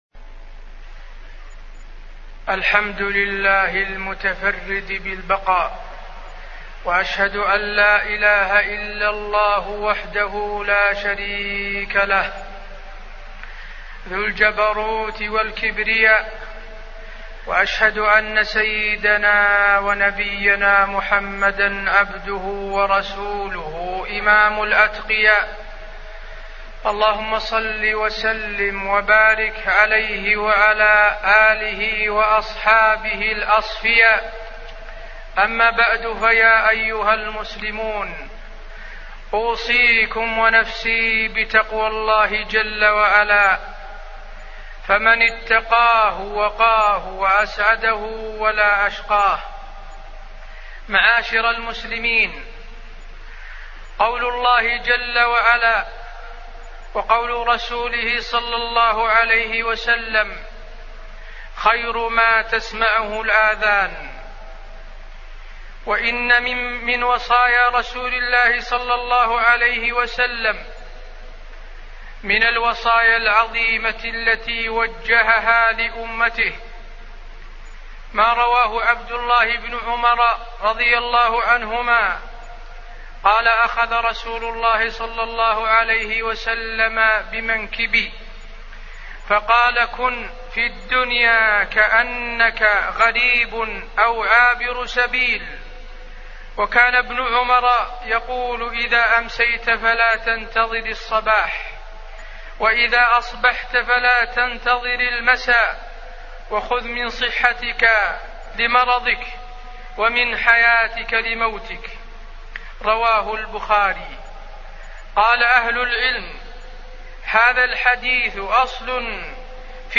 تاريخ النشر ٢٢ رجب ١٤٢٩ هـ المكان: المسجد النبوي الشيخ: فضيلة الشيخ د. حسين بن عبدالعزيز آل الشيخ فضيلة الشيخ د. حسين بن عبدالعزيز آل الشيخ كن في الدنيا كأنك غريب أو عابر سبيل